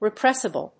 音節re・press・i・ble 発音記号・読み方
/rɪprésəbl(米国英語)/